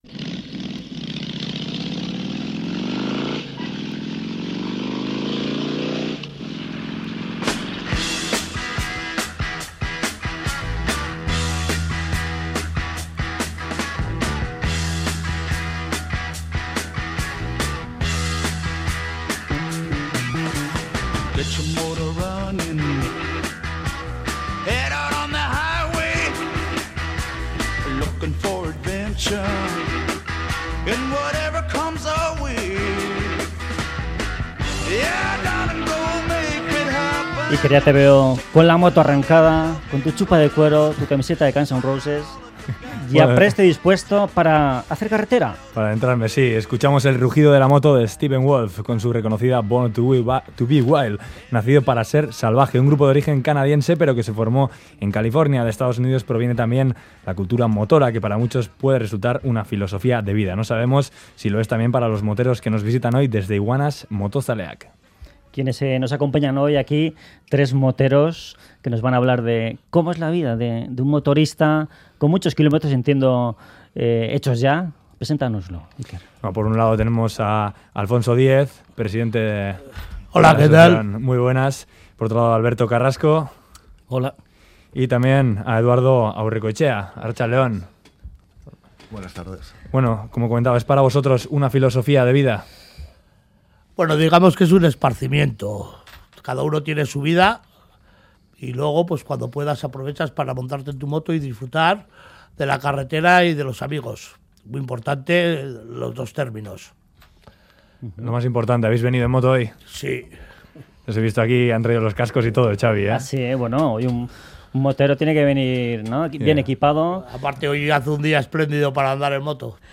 Audio: Tres miembros del grupo motero "Iguanas Motozaleak" han visitado hoy los estudios de Radio Euskadi para hablarnos de la cultura motera